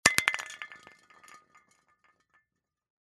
Звуки аэрозоля
Пустую аэрозоль выбросили подальше